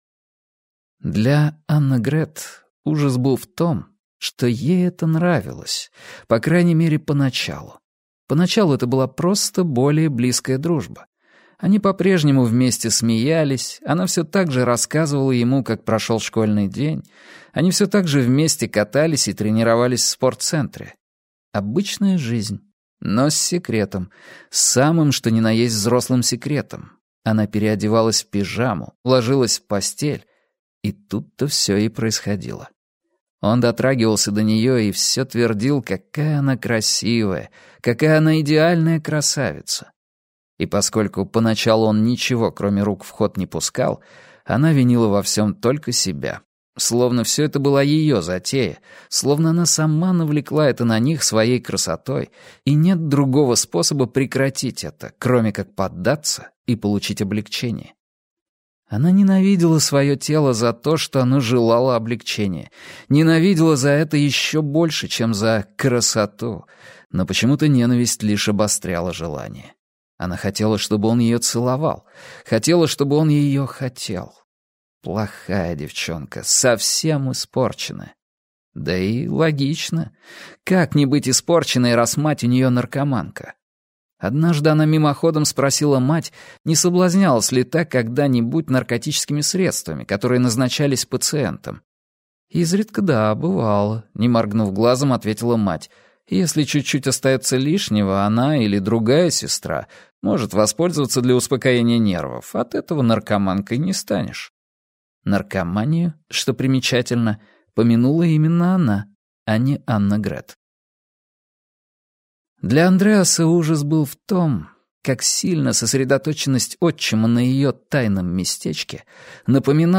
Аудиокнига Безгрешность - купить, скачать и слушать онлайн | КнигоПоиск